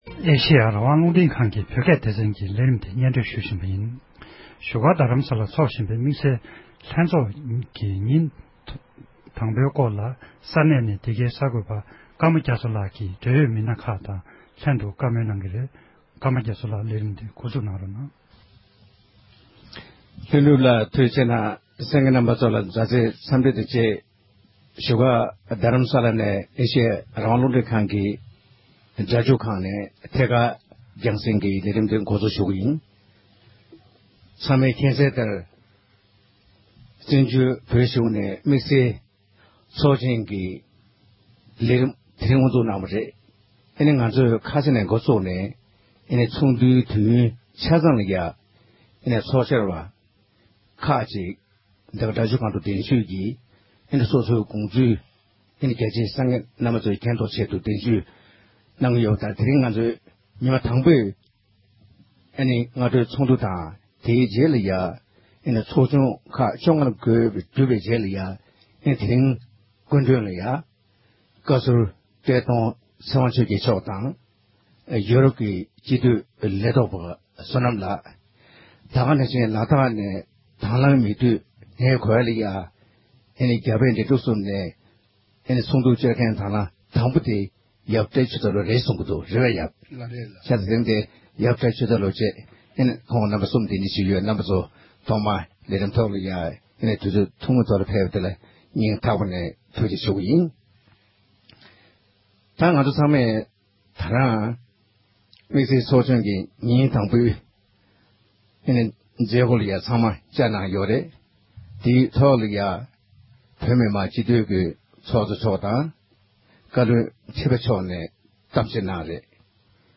འབྲེལ་ཡོད་མི་སྣ་ཁག་ཅིག་གི་ལྷན་དུ་བགྲོ་གླེང་གནང་བར་གསན་རོགས་གནང༌༎